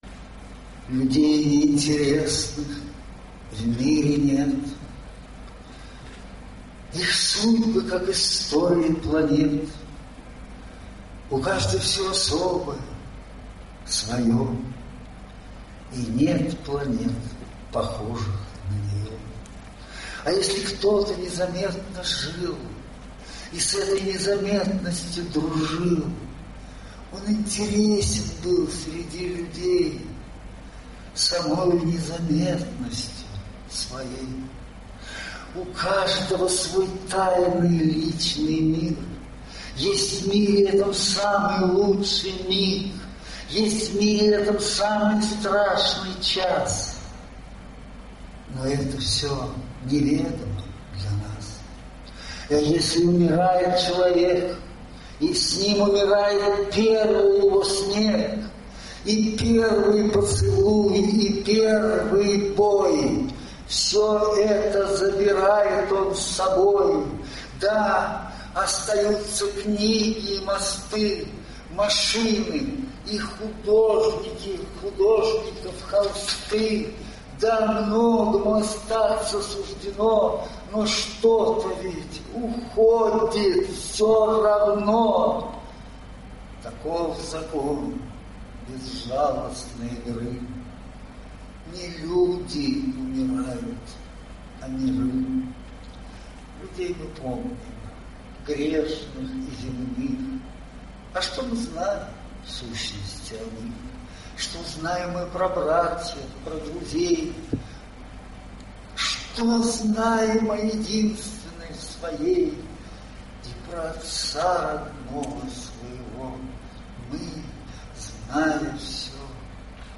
Прошло более полувека; теперь ему уже 84 года, и вот теперь он снова читает эти свои давнишние стихи (скачать):